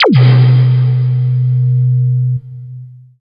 84 BLIP   -L.wav